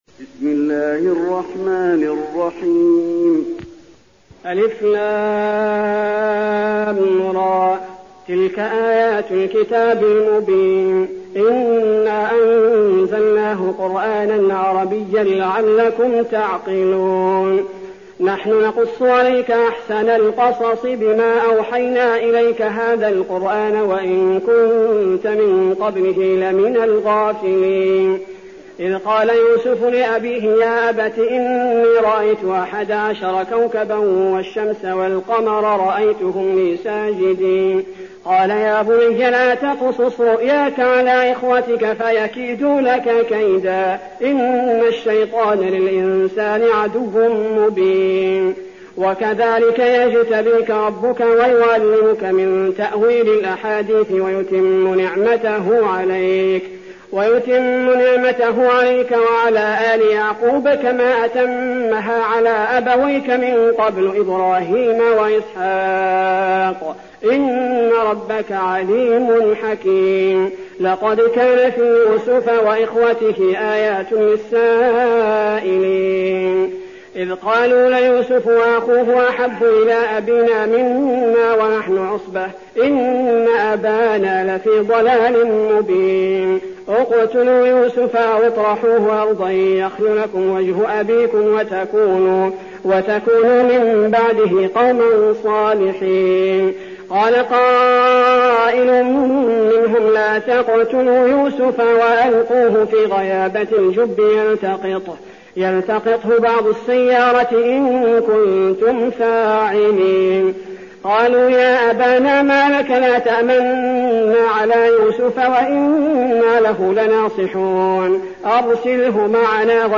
المكان: المسجد النبوي يوسف